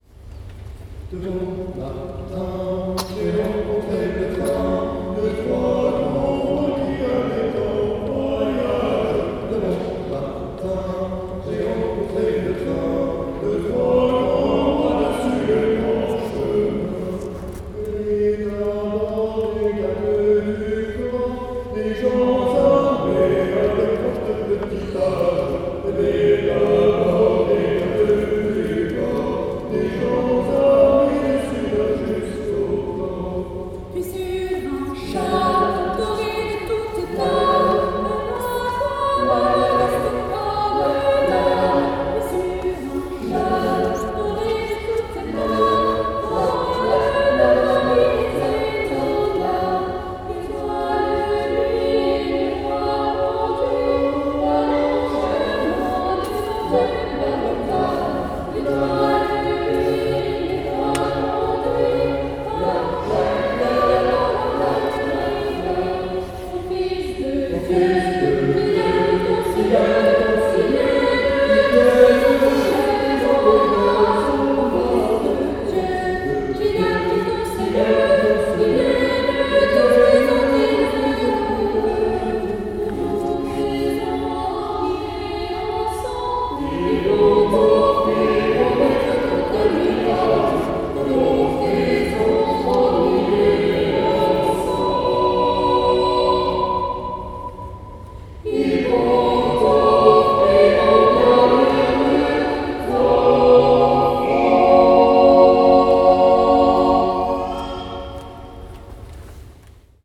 Orgue, trompettes et saxophone ont fait écho aux voix des choristes interprétant les traditionnels chants de Noël.
« L’année de l’ASSOEC s’est achevée une nouvelle fois par le concert de Noël des Jeunes Chrétiens du Vexin.
Nous sommes ravis de vous annoncer que le concert de Noël organisé par la chorale des jeunes chrétiens du Vexin, qui s’est tenu avec émotion et ferveur dans l’église de Chaumont-en-Vexin, a été un véritable succès.
La magie de Noël a envahi chaque coin de l’église, portée par la voix harmonieuse de la chorale et l’esprit festif partagé entre nous. Les chants de Noël résonnant sous les voûtes de l’église ont créé une atmosphère enchanteresse, propice à la célébration de cette période unique de l’année.